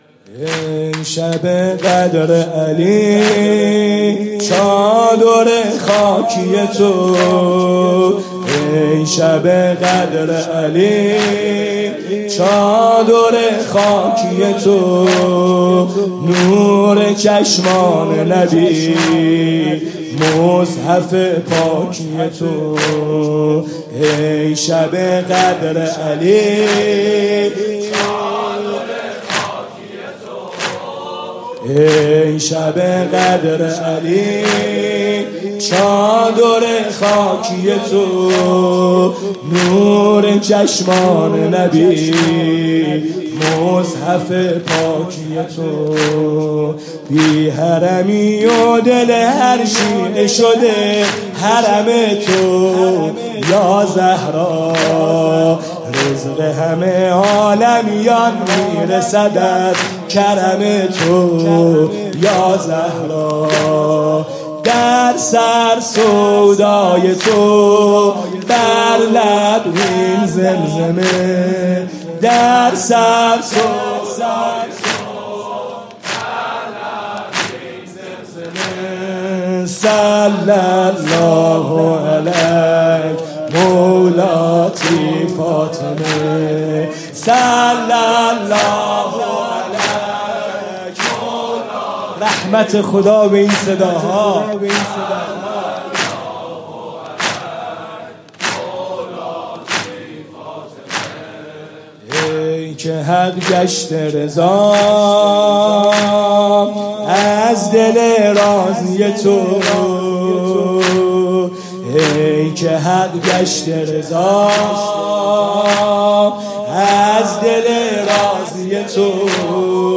شب اول فاطمیه دوم ۱۴۰۴
music-icon شور: اگه بدم و بین خوبا دیگه ندارم جا